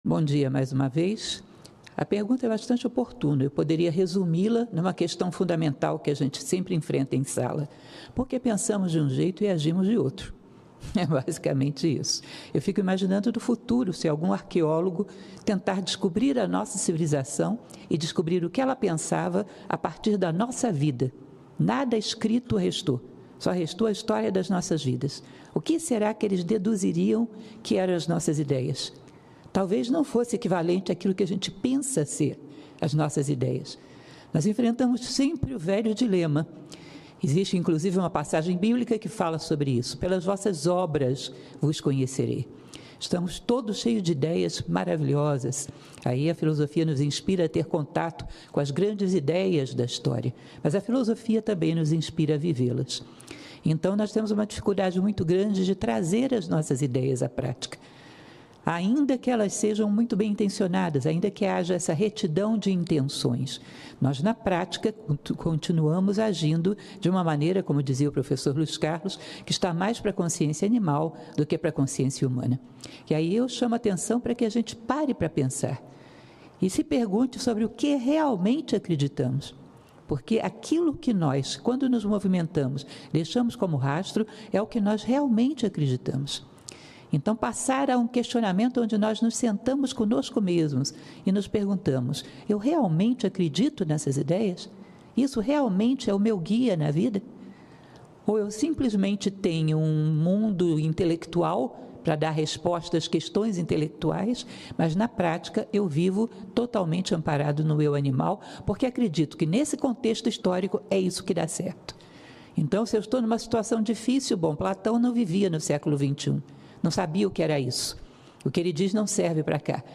Na segunda-feira, dia 18 de setembro, a Organização Internacional Nova Acrópole participou da sessão especial em homenagem ao Estoicismo no plenário do Senado Federal em Brasília.